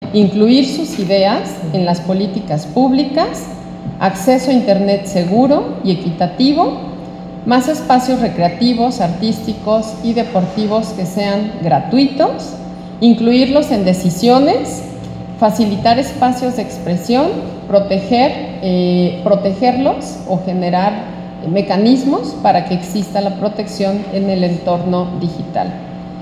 Habla Cynthia Patricia Cantero Pacheco, Secretaria de Planeación y Participación Ciudadana